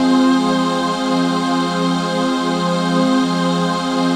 DM PAD2-57.wav